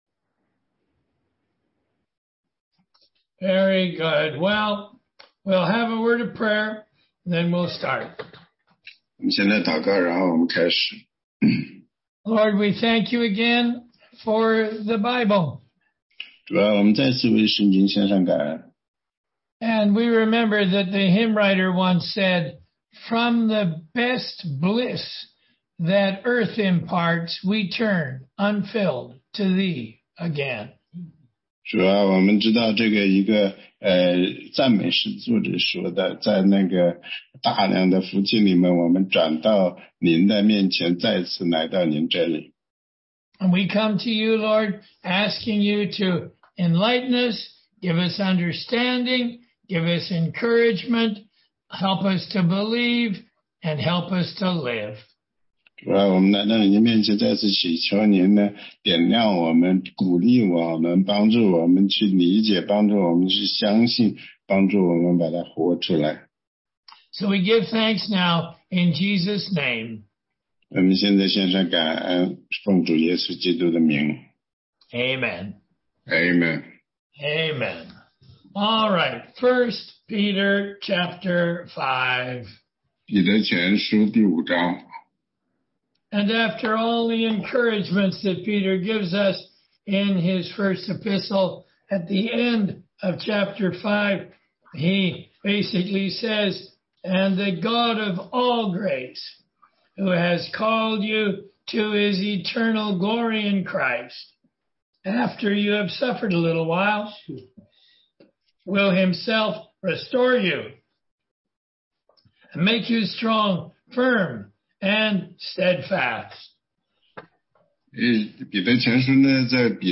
16街讲道录音 - 答疑课程